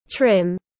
Gầy khỏe mạnh tiếng anh gọi là trim, phiên âm tiếng anh đọc là /trɪm/ .
Trim /trɪm/